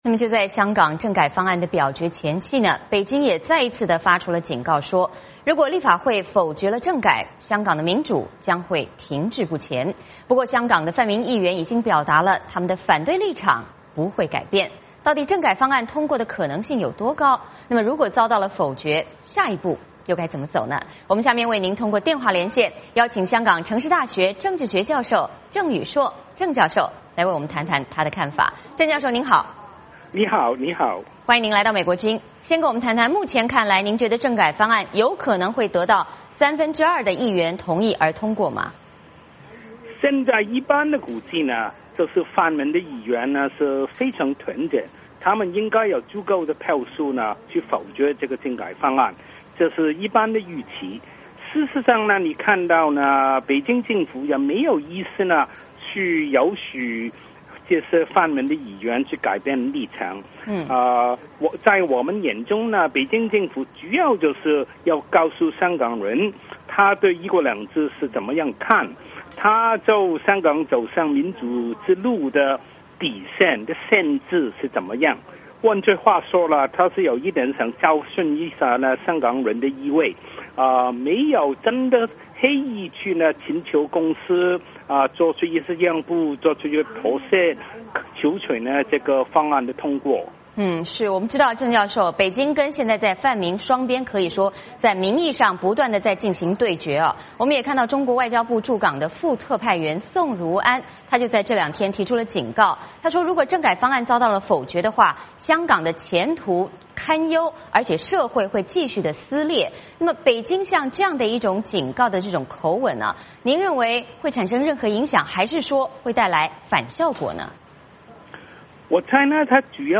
我们通过电话连线